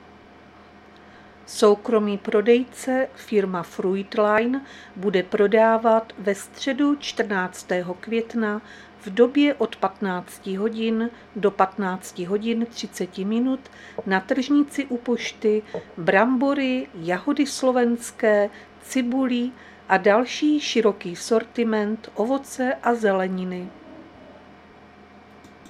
Záznam hlášení místního rozhlasu 13.5.2025
Zařazení: Rozhlas